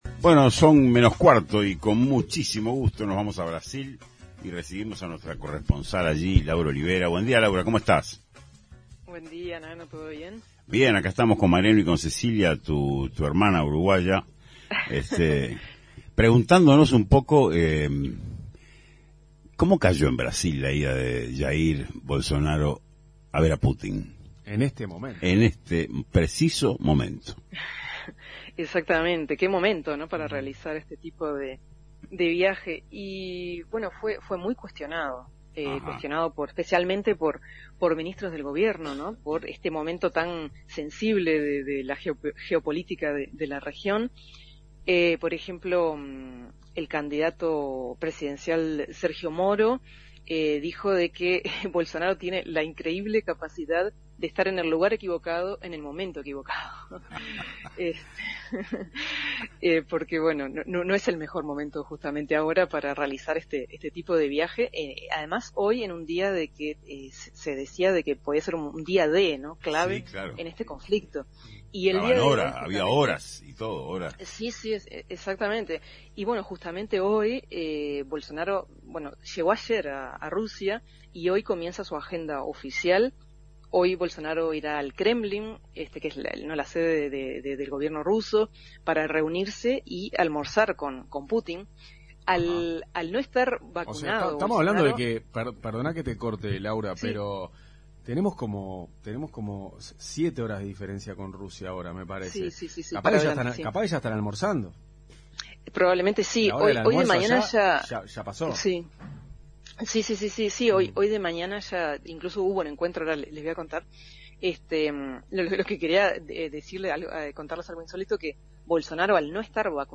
informe completo